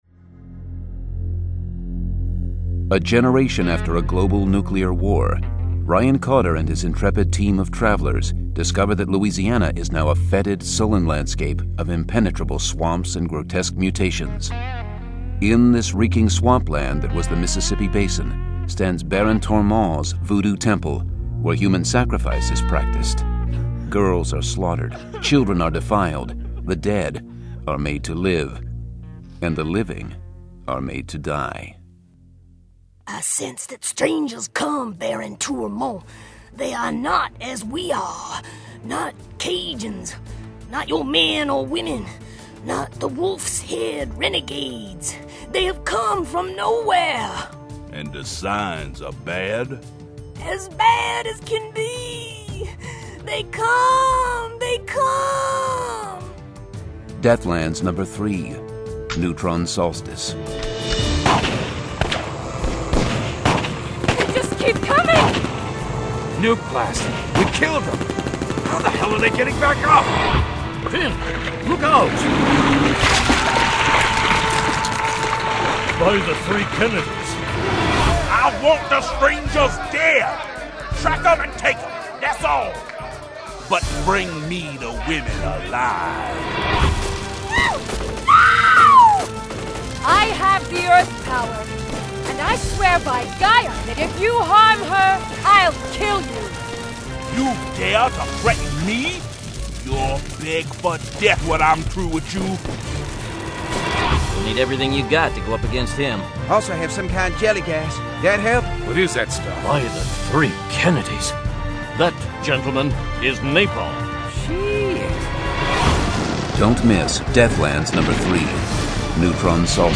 Deathlands 3: Neutron Solstice [Dramatized Adaptation]